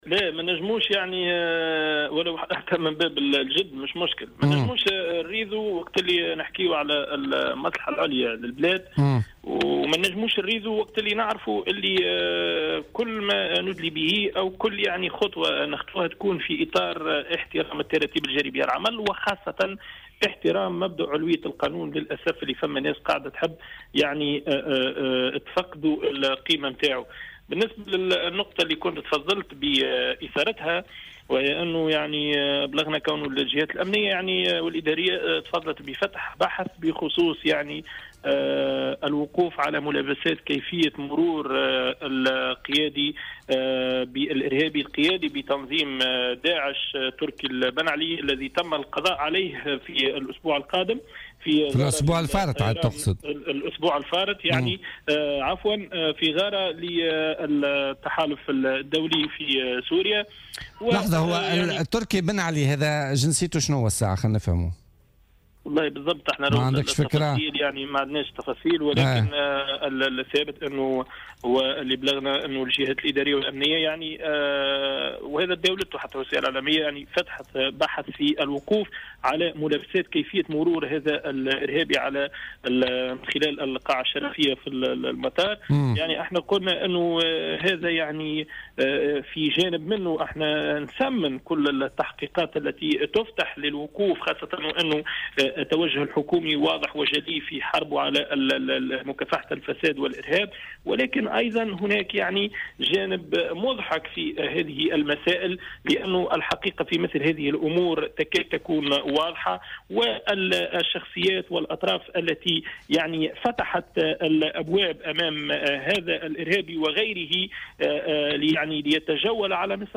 وأضاف في مداخلة له اليوم في "برنامج "بوليتيكا" أنه تم فتح تحقيق في ملابسات دخول "البنعلي" إلى تونس خلال شهر رمضان 2012.